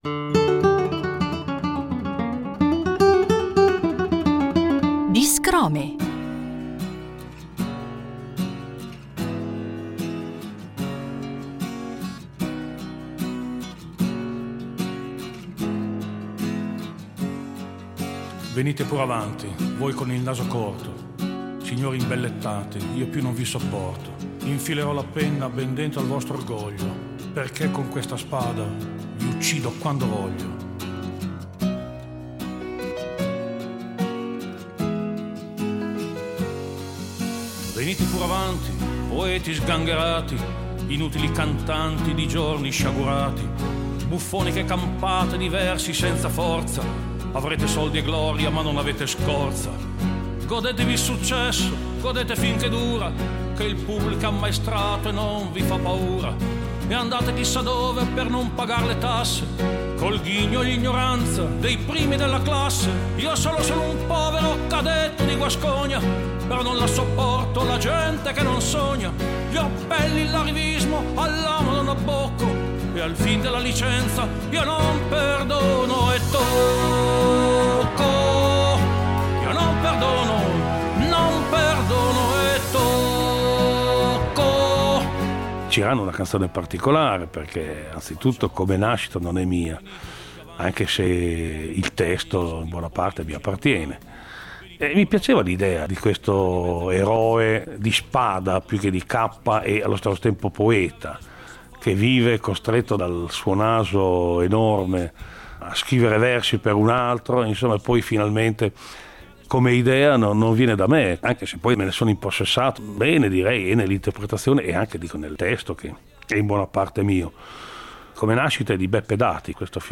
Una preziosa serie di chicche tratte dai nostri archivi: il grande cantautore emiliano Francesco Guccini introduce a modo suo alcune delle sue canzoni più note e amate dal pubblico.